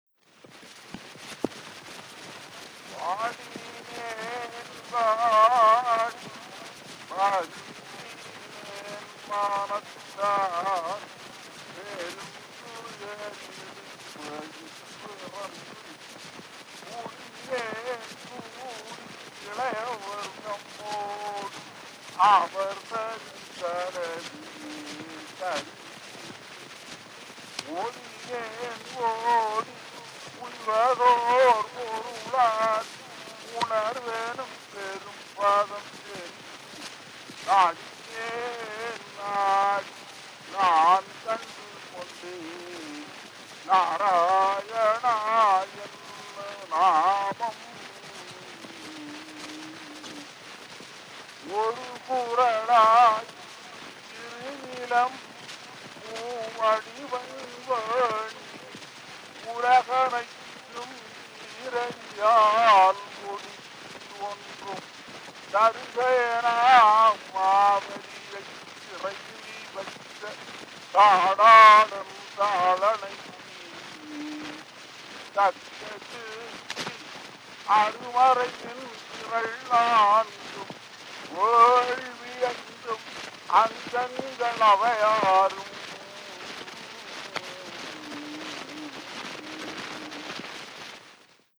chiefly recitations of classical Tamil poetry
Historical sound recordings